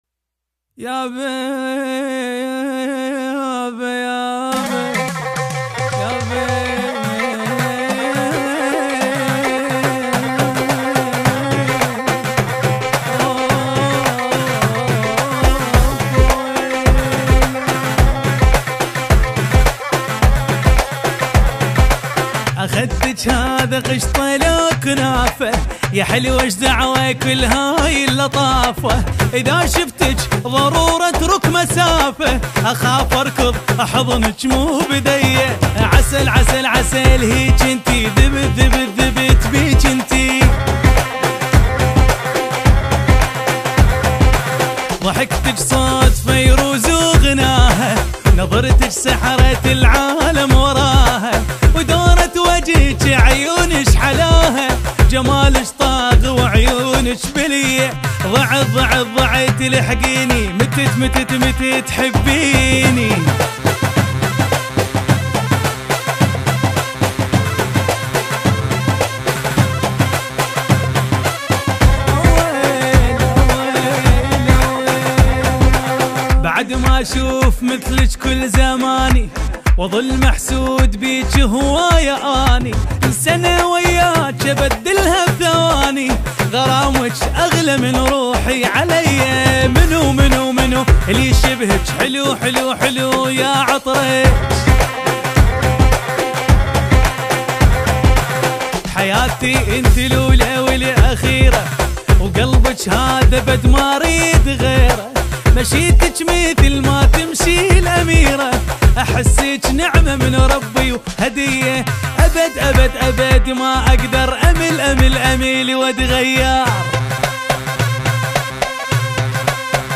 إسم القسم : اغاني عراقية